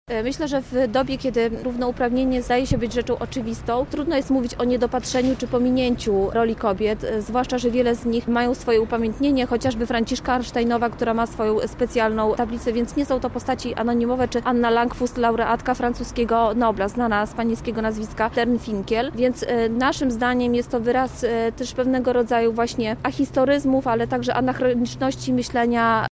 z Partii Razem